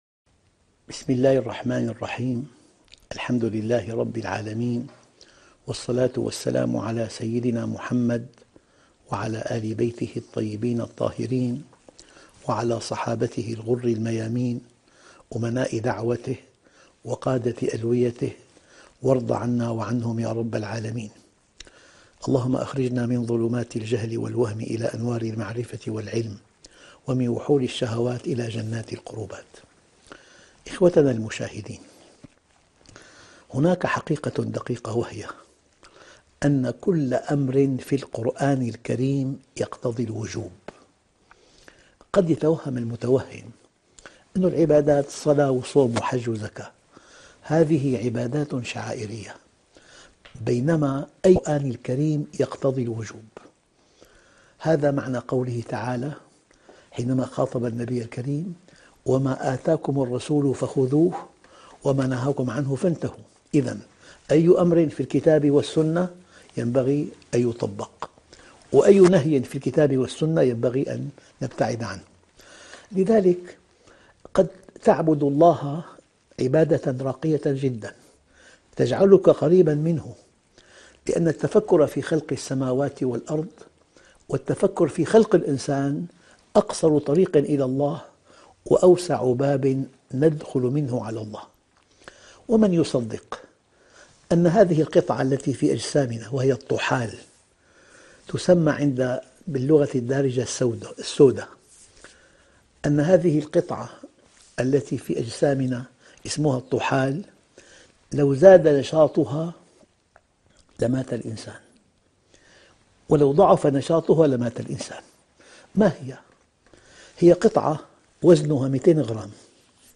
شبكة المعرفة الإسلامية | الدروس | اوامر قرانية |محمد راتب النابلسي